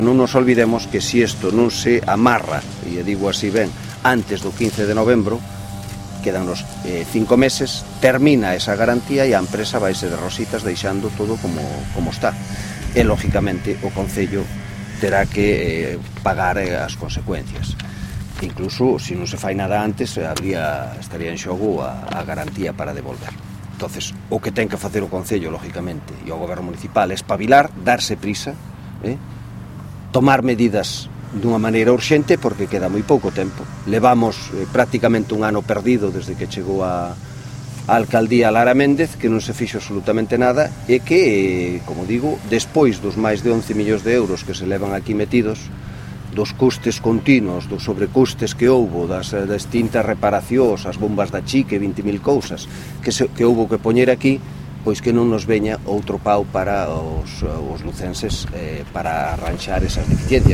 O voceiro do Grupo Municipal do Partido Popular no Concello de Lugo, Jaime Castiñeira, advirtiu esta mañá en rolda de prensa “doutro probable sobrecuste” na obra do Museo Interactivo da Historia de Lugo (MIHL).